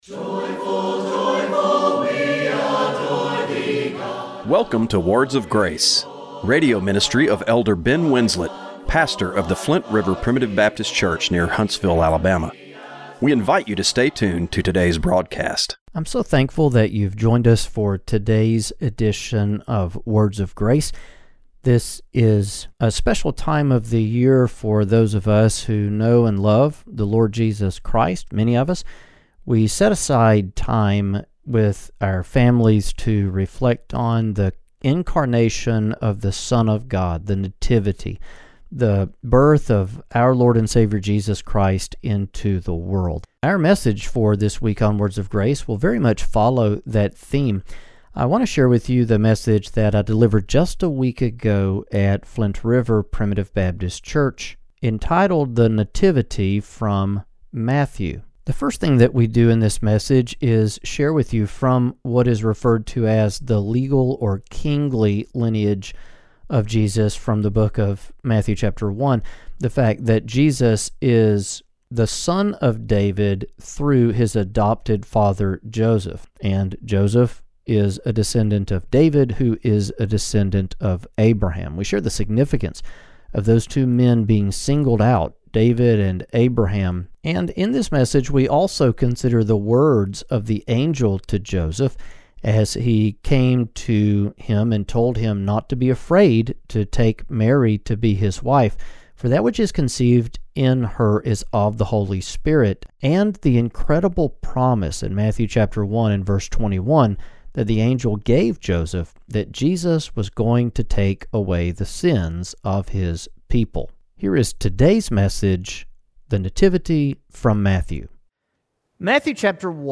Radio broadcast for December 22, 2024.